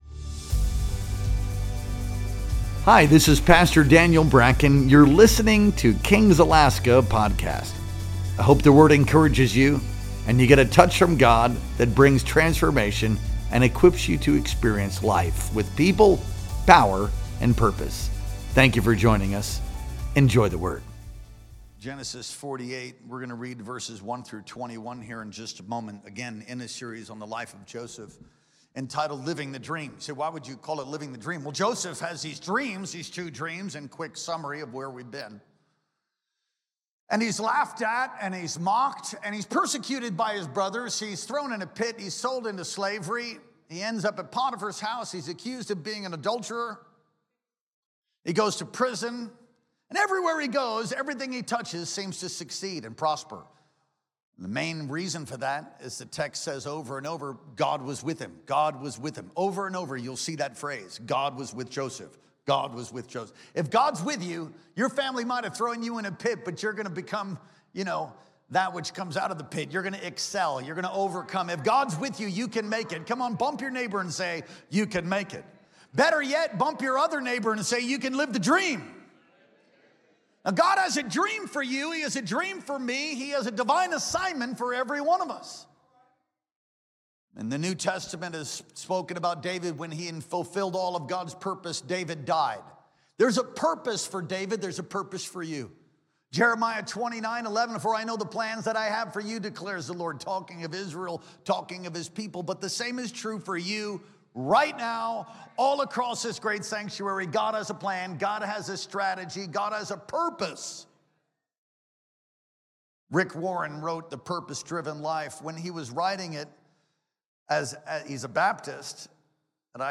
Our Sunday Worship Experience streamed live on June 29th, 2025.